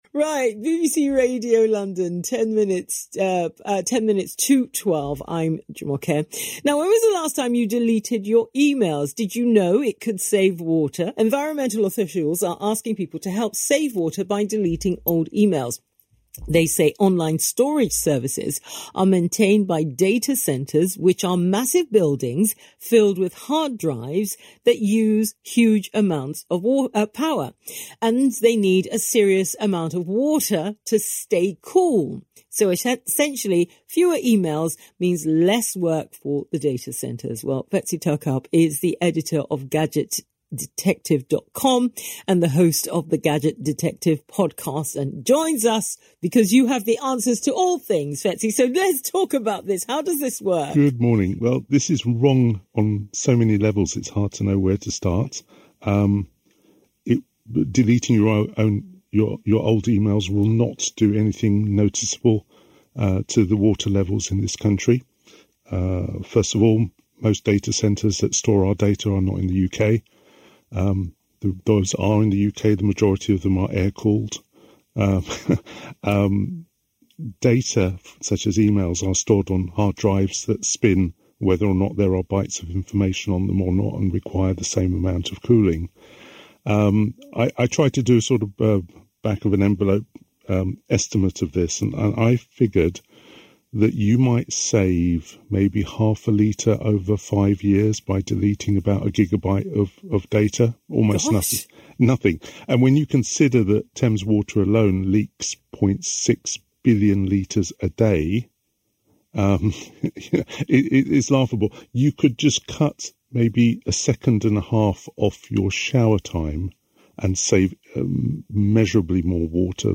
tech news broadcasts